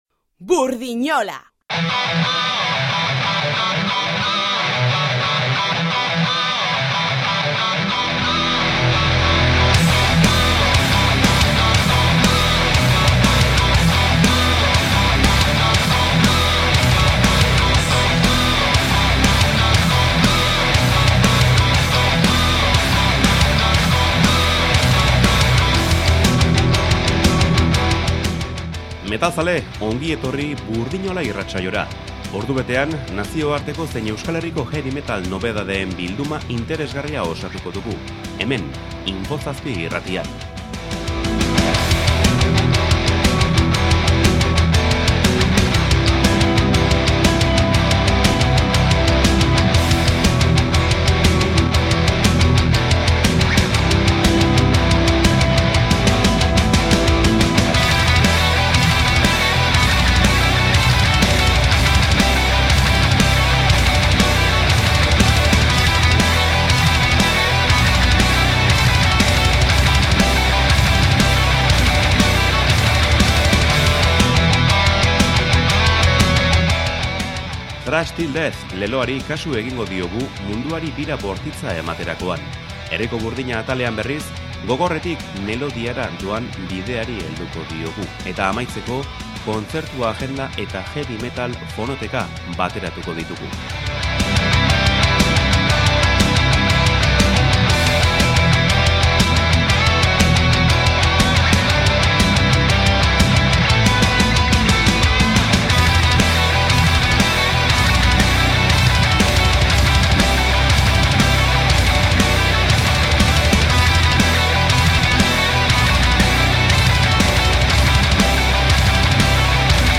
BURDINOLA: Kontraesanik gabeko kontrastea sortu nahi du heavy metal saioak